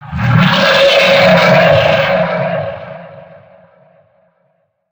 swamp_growl_1.ogg